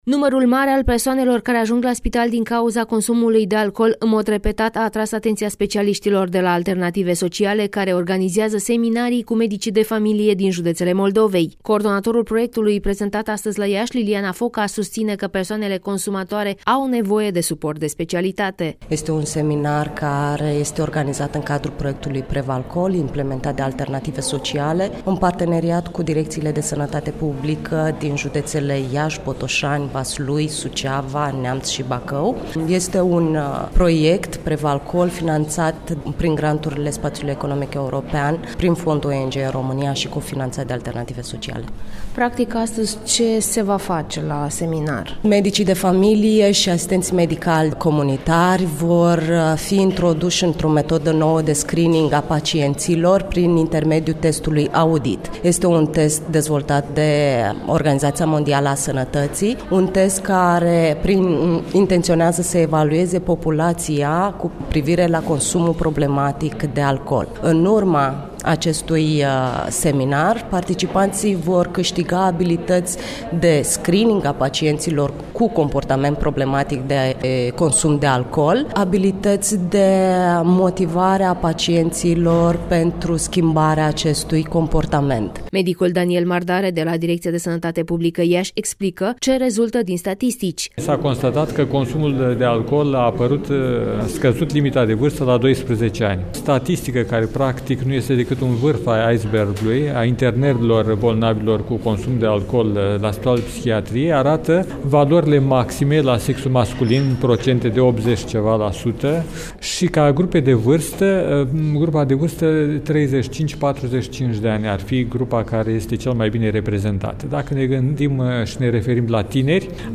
IAŞI: (Reportaj) 900 de persoane internate la Spitalul de Psihiatrie Socola în 2014